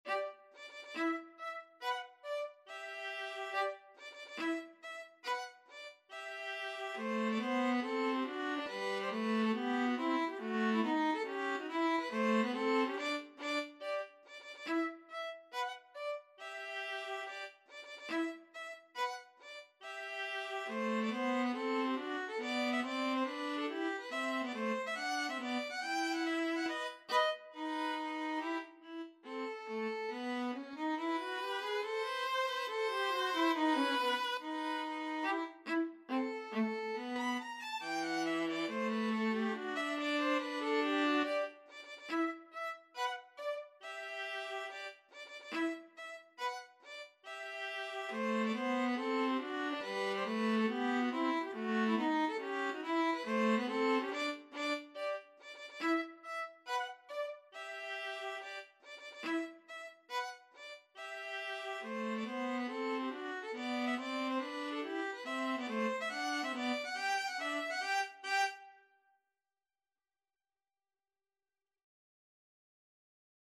4/4 (View more 4/4 Music)
Tempo di marcia =140
Classical (View more Classical Violin-Viola Duet Music)